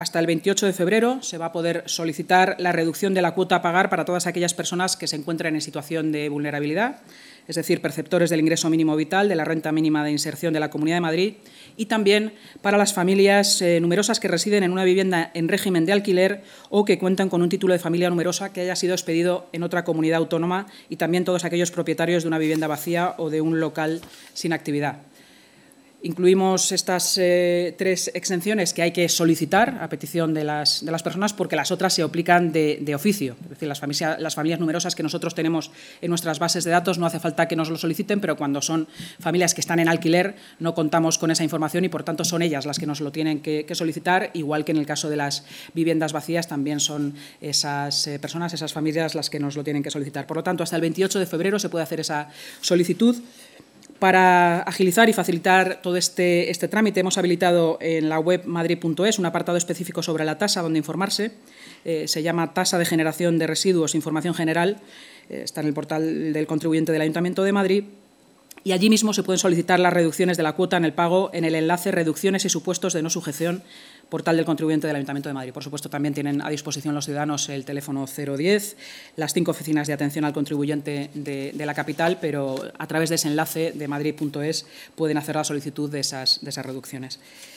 Nueva ventana:Declaraciones de la vicealcaldesa de Madrid y portavoz municipal, Inma Sanz, sobre las solicitudes de las reducciones en la cuota de la nueva tasa de residuos